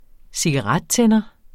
Udtale [ -ˌtεnʌ ]